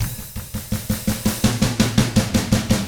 164ROCK F2-L.wav